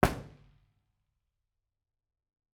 IR_EigenmikePL001B1_processed.wav